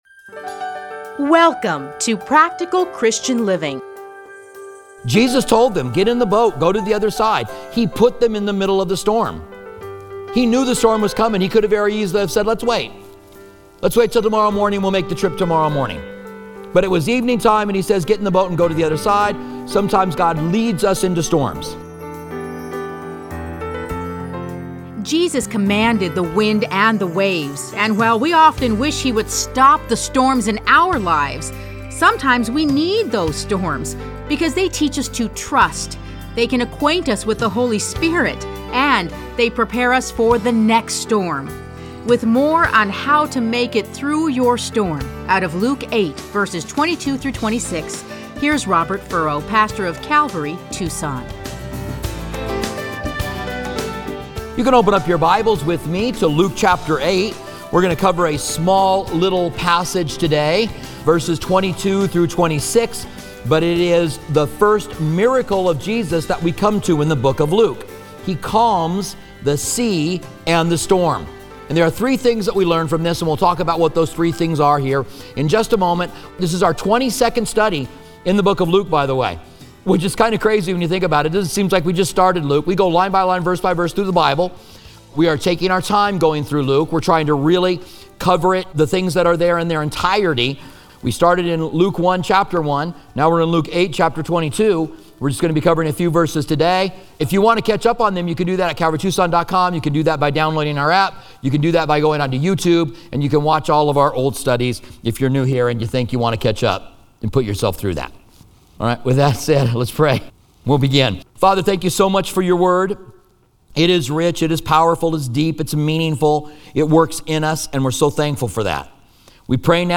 Listen to a teaching from Luke 8:22-26.